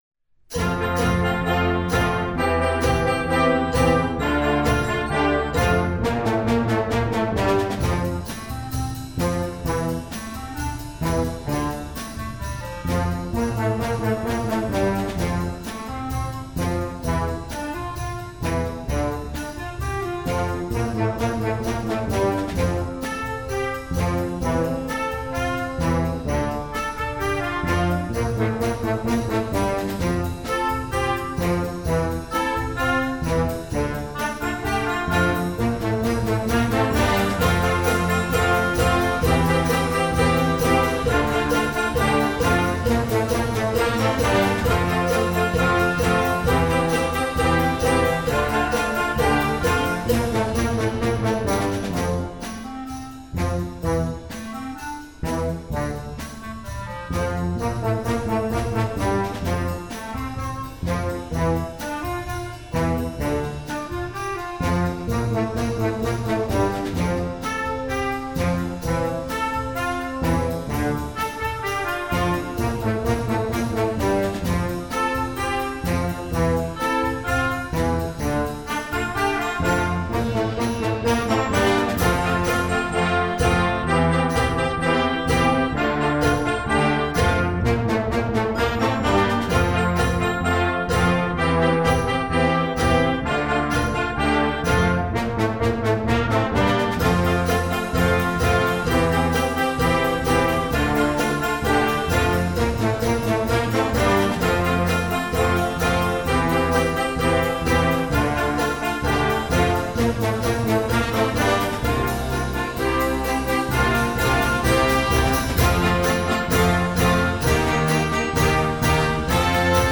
Gattung: Moderner Einzeltitel für Jugendblasorchester
Besetzung: Blasorchester